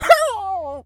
Animal_Impersonations
dog_hurt_whimper_howl_02.wav